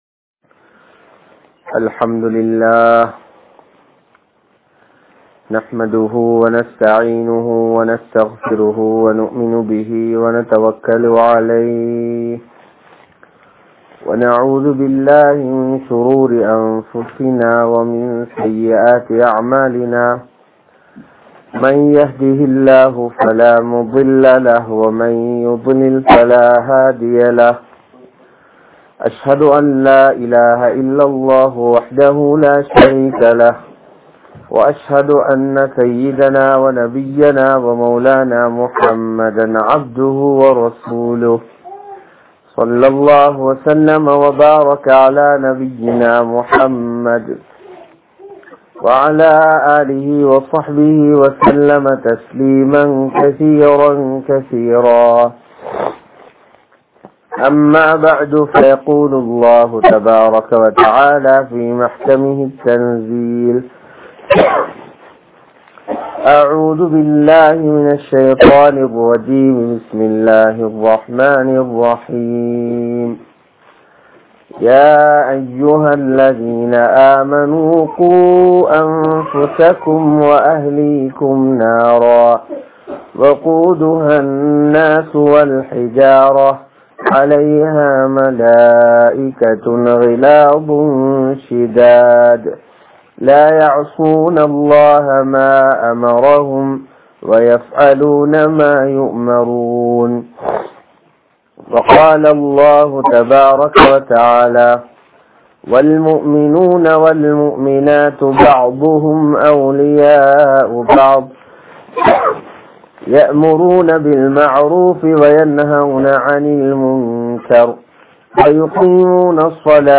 Dheen Kidaipathu Niumath Aahum (தீண் கிடைப்பது நிஃமத் ஆகும்) | Audio Bayans | All Ceylon Muslim Youth Community | Addalaichenai